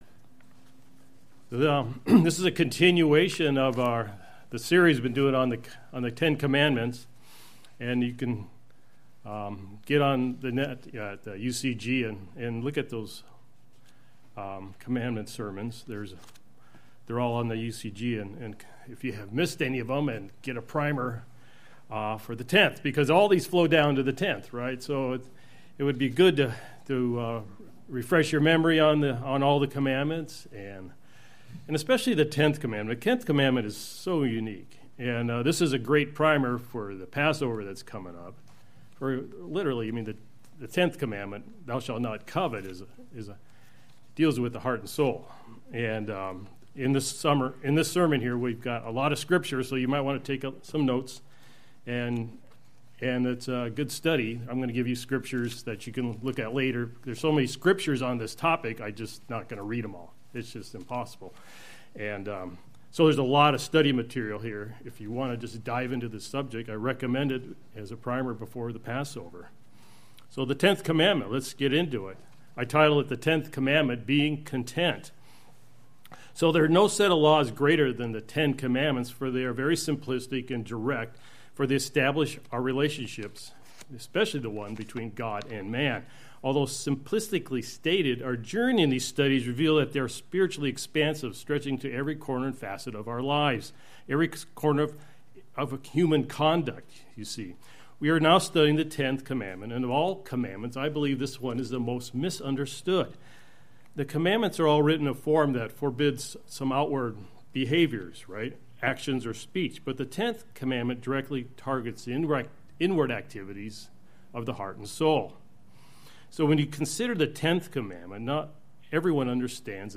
The way of God is centered around giving and sharing with others and relying on God for our daily needs. It involves being content and grateful for everything we have. The sermon sheds light on God's way of faith and trust and encourages the listener to follow that path.